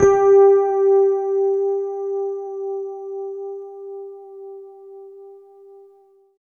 47 RHOD G4-R.wav